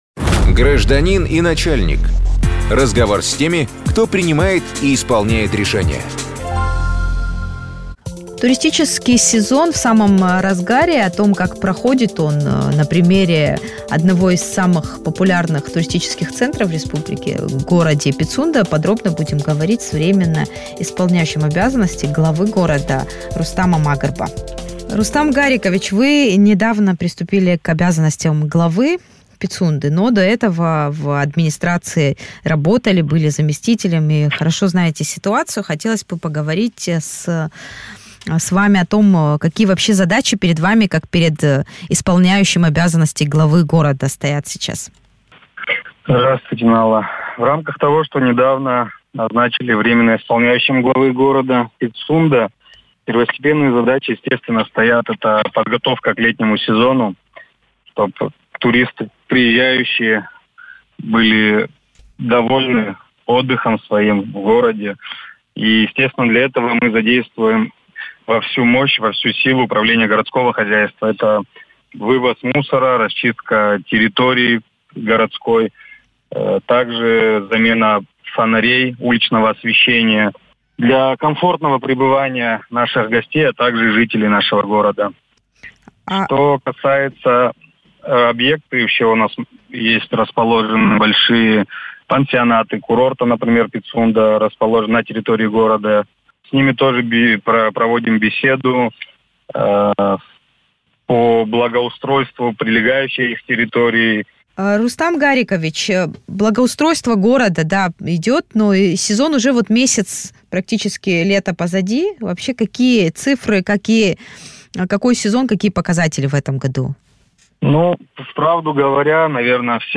Турпоток, бюджет, инфраструктура Пицунды: разговор с градоначальником